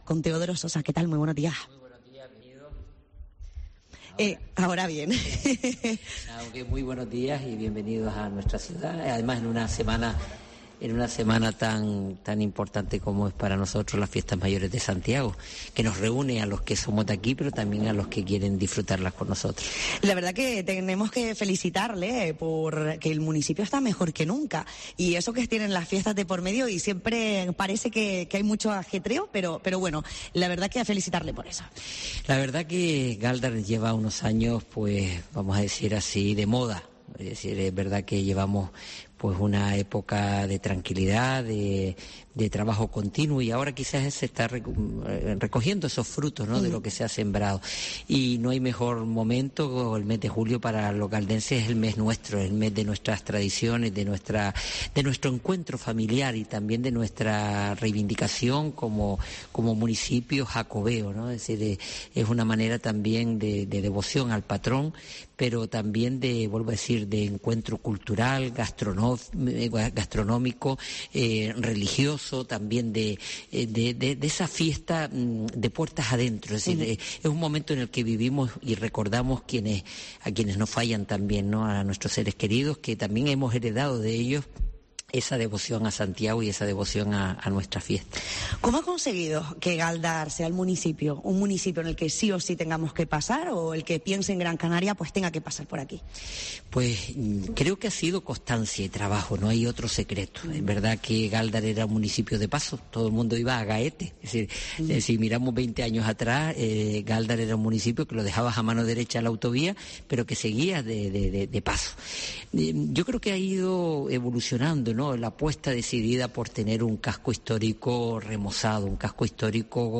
Teodoro Sosa, alcalde de Gáldar
La Mañana de COPE Gran Canaria se desplazó a Gáldar para compatir los actos conmemorativos a la festividad del patrón, Santiago de los Caballeros.